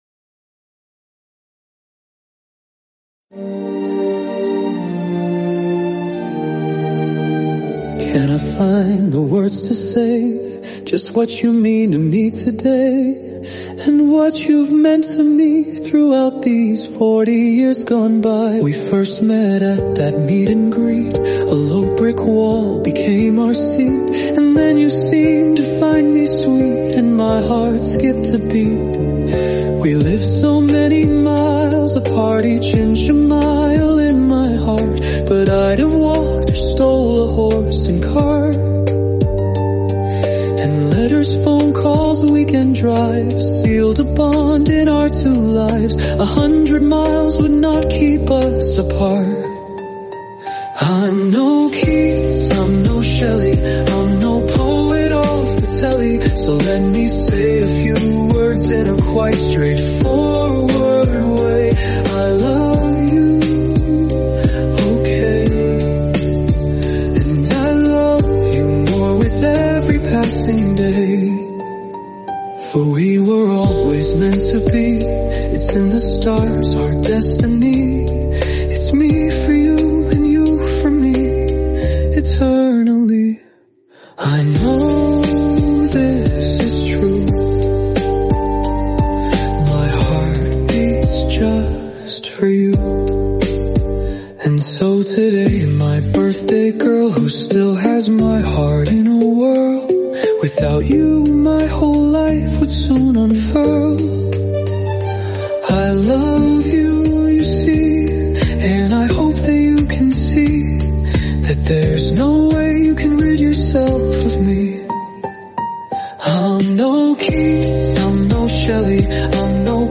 I used AI (kill me now) to set this to music for her...
No, that's not me singing.... it's, effectively, a robot!